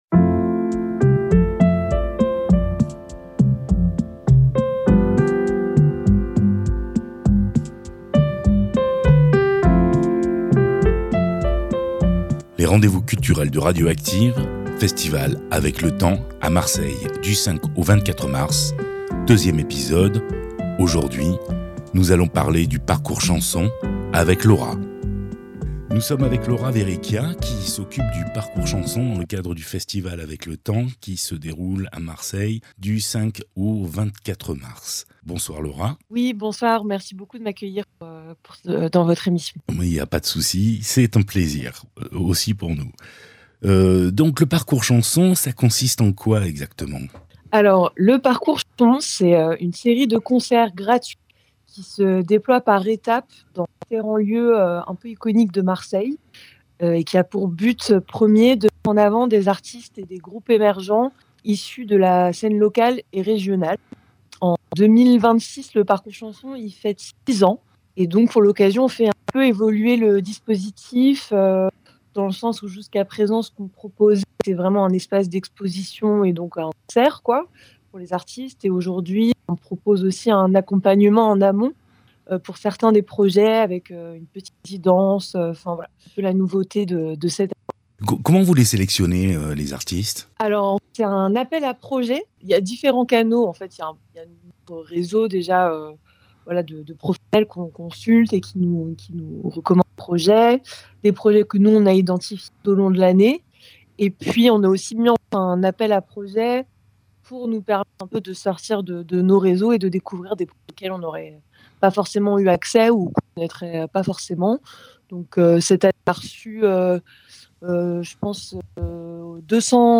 À l’occasion du festival Avec le Temps, qui aura lieu du 5 au 24 mars à Marseille, consacré à la chanson française, Radio Active vous propose des entretiens avec les principaux acteurs de l’événement.